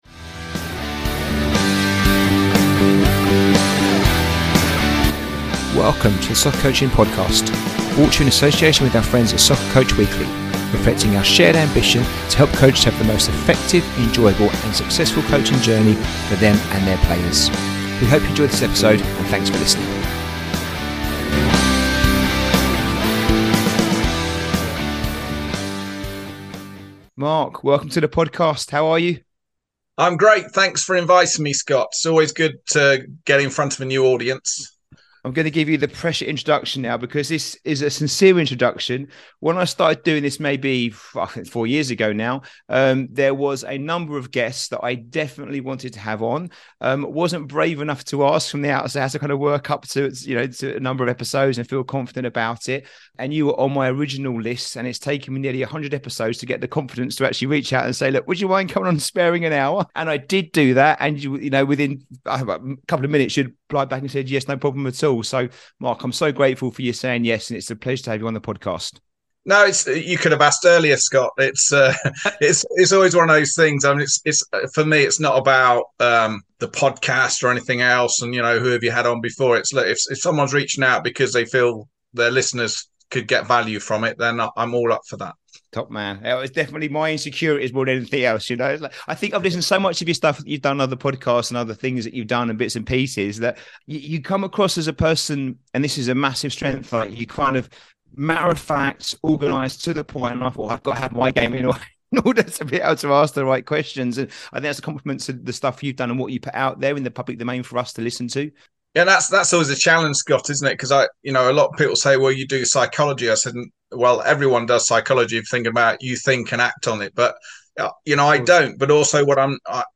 Episode 100 - Facilitating Self Managing Teams, a conversation